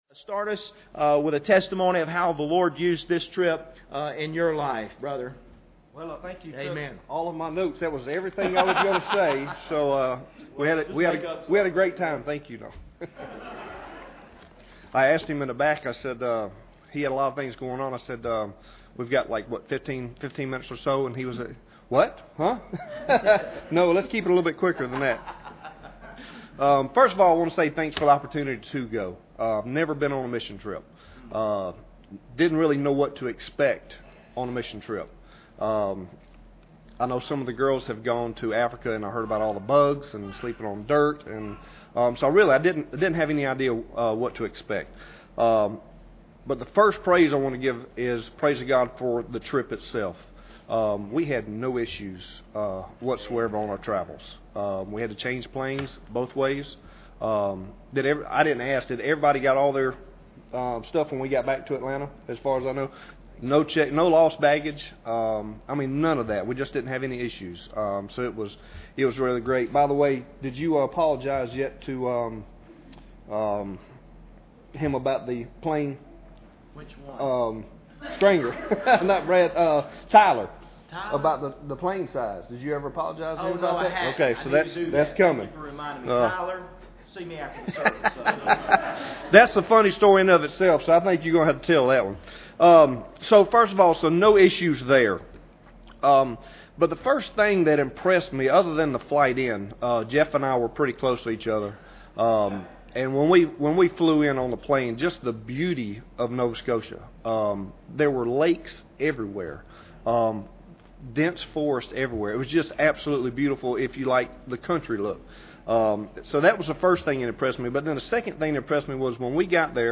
Members of the Nova Scotia Team share testimonies from the July Missions Trip.
Service Type: Sunday Evening